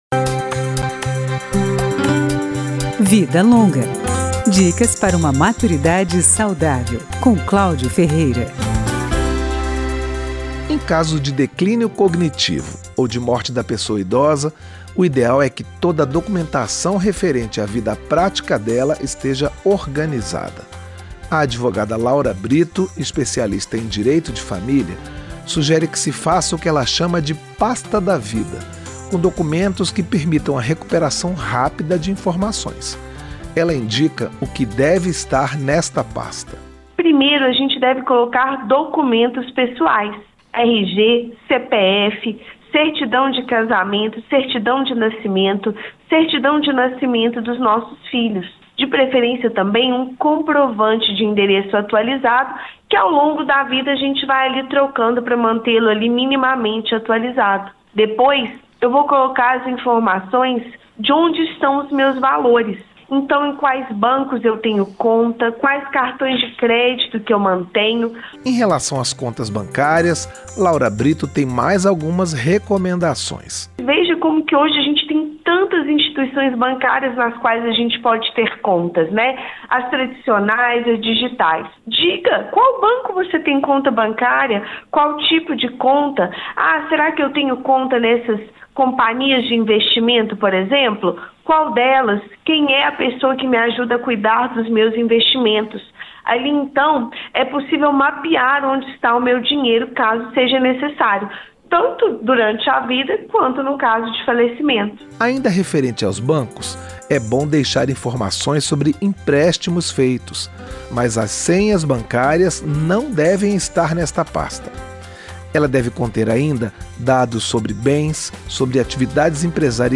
Uma advogada especialista em Direito de Família recomenda que documentos do idoso sejam organizados em uma “pasta da vida”, que pode ser útil em caso de declínio cognitivo ou morte da pessoa, diante da necessidade de ter informações sobre a vida prática dela.
Profissionais de várias áreas falam sobre alimentação, cuidados com a saúde, atividades físicas, consumo de drogas (álcool, cigarro) e outros temas, sempre direcionando seus conselhos para quem tem mais de 60 anos.
Tudo em uma linguagem direta, mas dentro de uma abordagem otimista sobre a terceira idade.